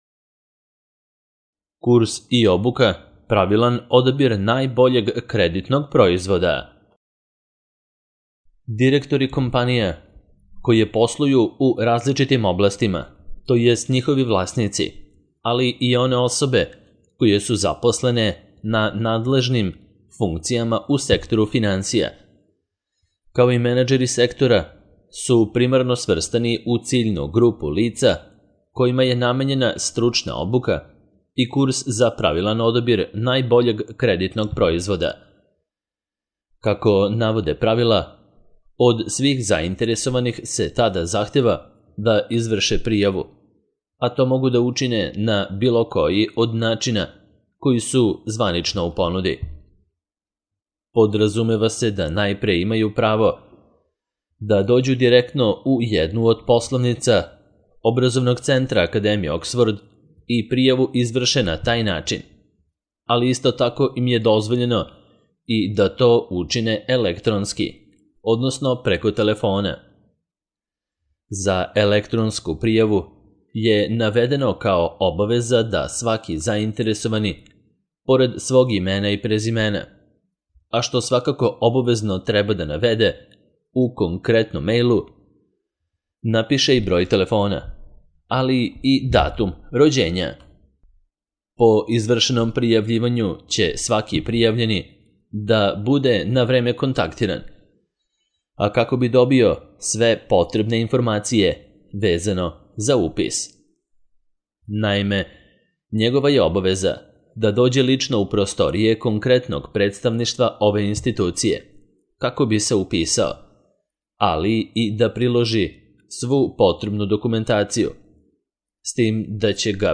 Pravilan odabir najboljeg kreditnog proizvoda - Audio verzija